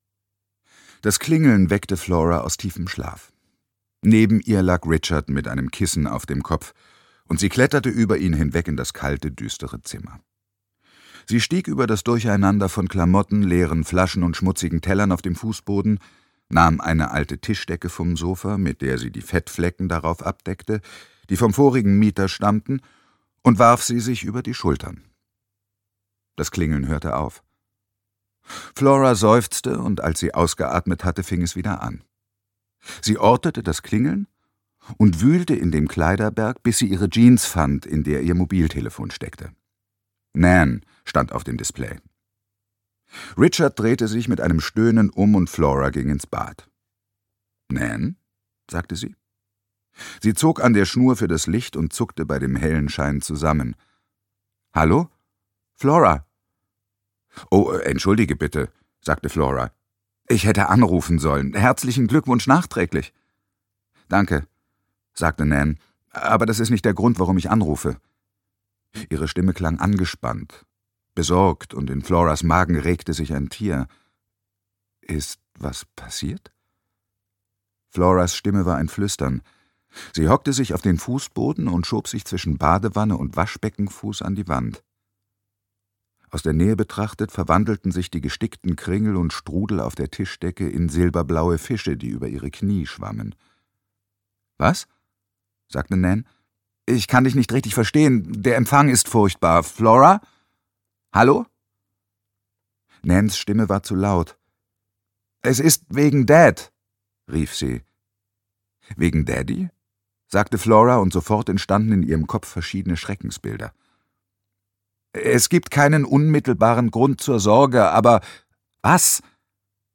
Eine englische Ehe - Claire Fuller - Hörbuch